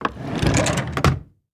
Drawer Close 2 Sound
household